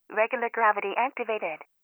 Regular Gravity Activated.wav